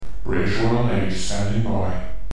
Tell me if you like for voiceovers. I did this one myself using audacity and my voice.
sound_test_british_navy_207.mp3